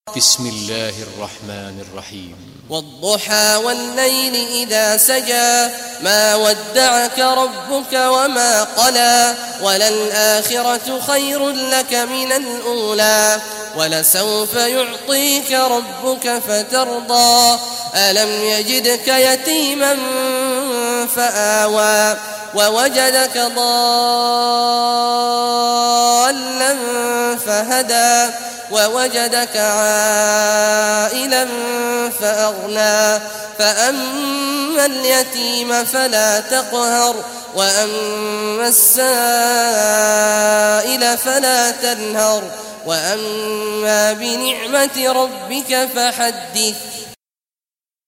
Surah Ad-Duha Recitation by Sheikh Awad Juhany
Surah Ad-Duha, listen or play online mp3 tilawat / recitation in Arabic in the beautiful voice of Sheikh Abdullah Awad Al Juhany.